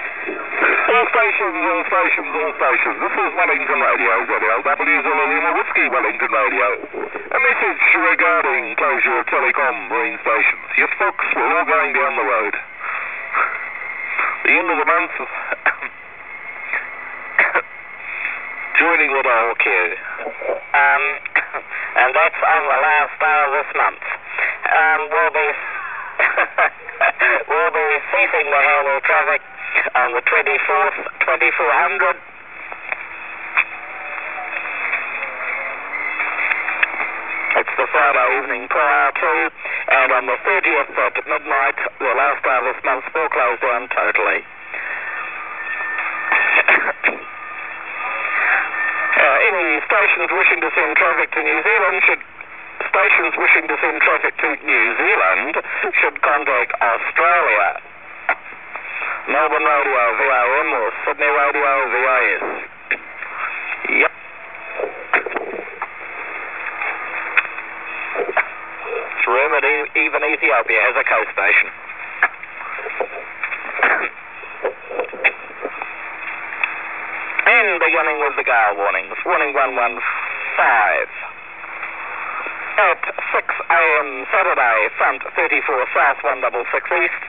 Some historic sounds mainly of New Zealand marine radiotelegraphy from 1993.
Wellingtonradio broadcasts an emotional notice of it's closure on 2153khz(512kb)
All the recordings were done while the ARAHURA was in the Cook Strait / Wellington / Picton area.
Wellingtonradio was at all times within about 30 miles.